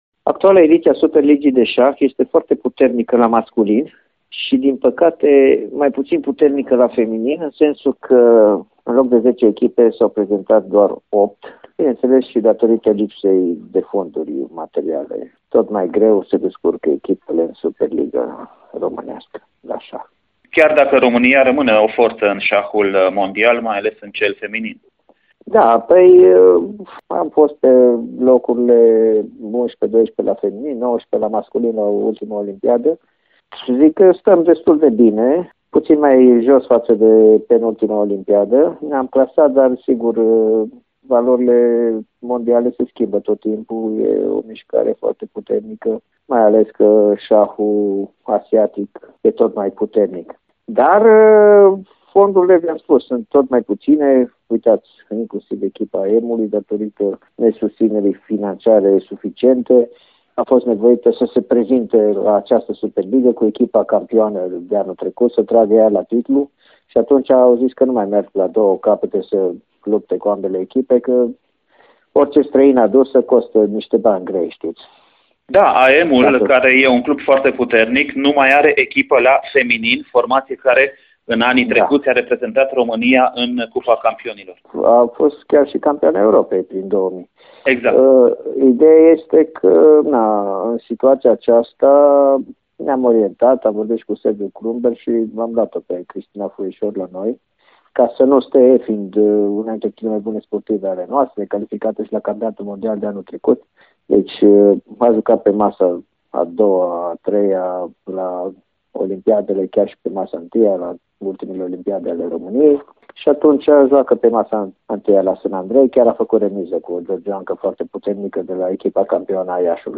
Interviul complet